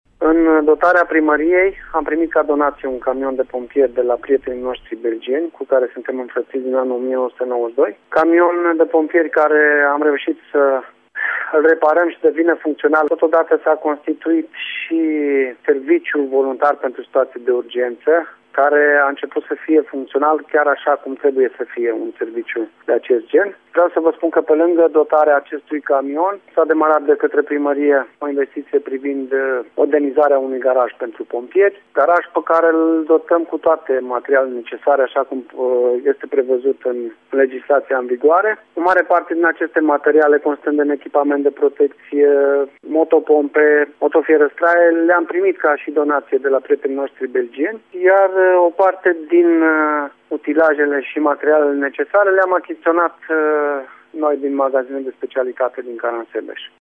La primăria Slatina Timiş a devenit funcţional Serviciul Voluntar pentru Situaţii de Urgenţă. Primarul localităţii Gheorghe Roma ne-a declarat că administraţia locală a primit ca donaţie o autoutilitară pentru stins incendii din partea comunităţii din Geel, Belgia, care ajută comuna de pe Valea Timişului de 20 de ani de zile, în baza relaţiilor de înfrăţire.